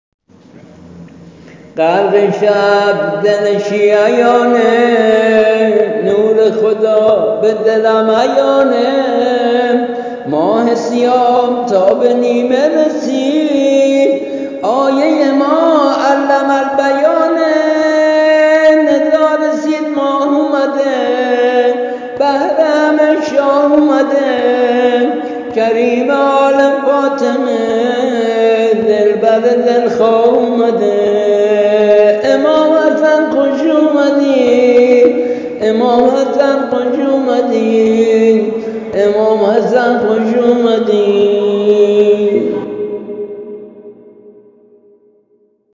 با سبک و ملودی جدید اثر شاعر سرود